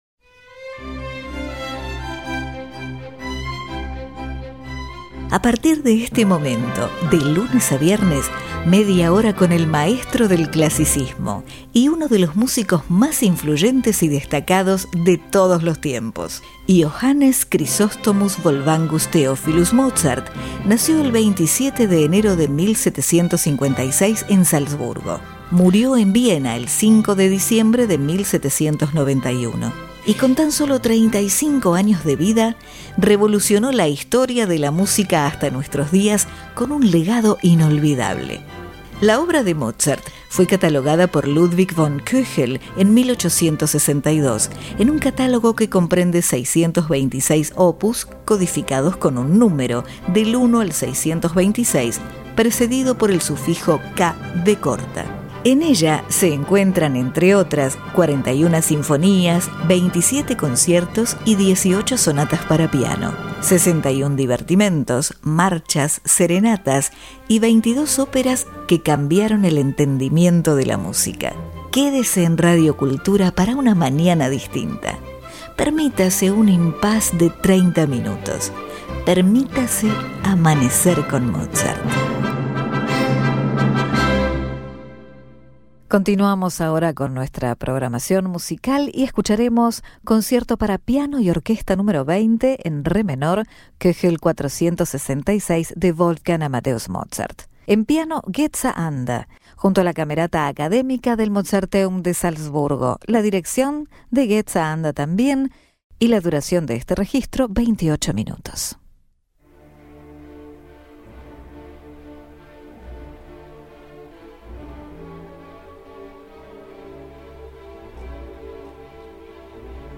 Concierto Para Piano Y Orquesta Nº 20 En Re Menor K. 466 Geza Anda(Piano) Camerata Academica Del Mozarteum De Salzburgo